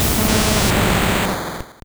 Cri de Steelix dans Pokémon Or et Argent.